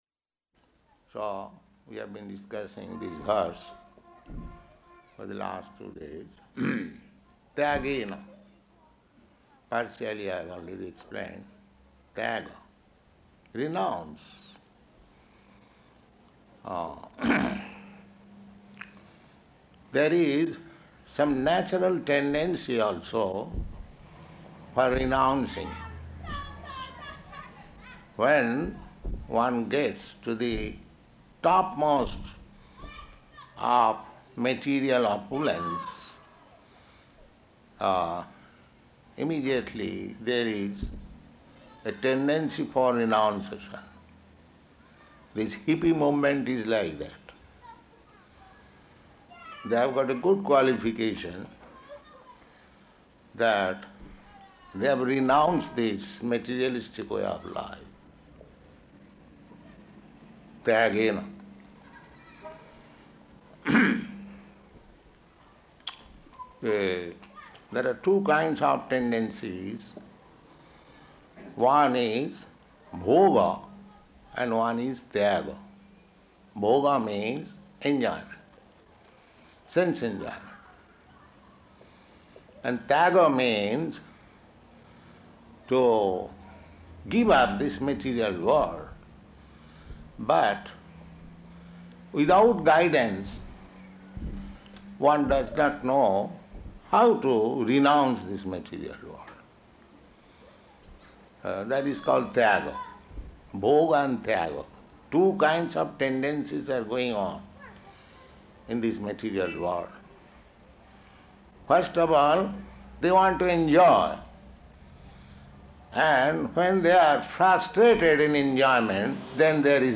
Śrīmad-Bhāgavatam 6.1.13–14 --:-- --:-- Type: Srimad-Bhagavatam Dated: July 27th 1971 Location: New York Audio file: 710727SB-NEW_YORK.mp3 Prabhupāda: So we have been discussing this verse for the last two days.